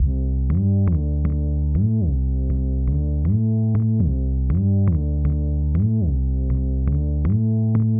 合成低音
描述：用Massive制作的低音。没有任何效果，只是原始的，你希望的任何类型的风格 可用于舞蹈，电子，房子...
Tag: 120 bpm Dance Loops Bass Synth Loops 1.35 MB wav Key : Unknown